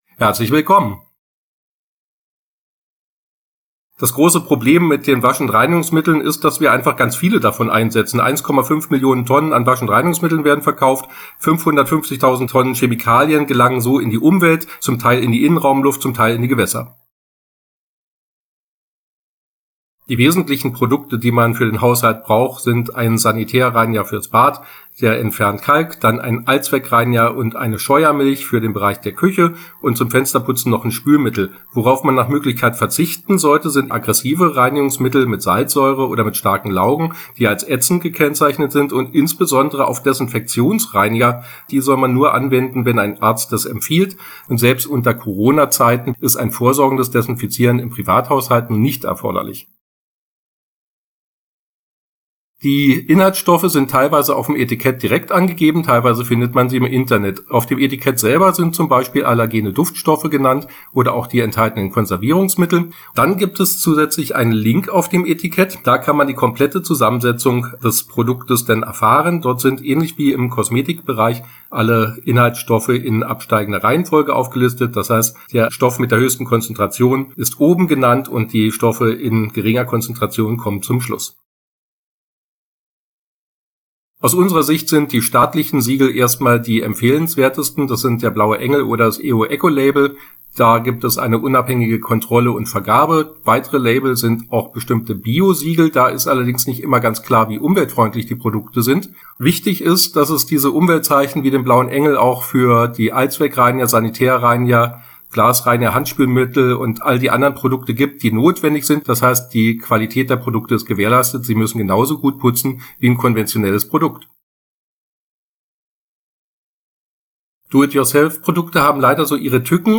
O-Töne (2:36 Minuten)